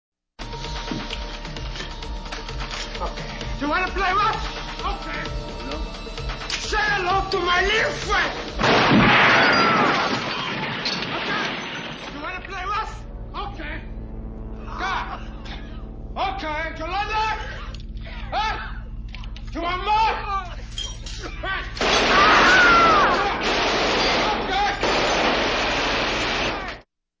El diálogo se mantiene en todo momento en el canal central.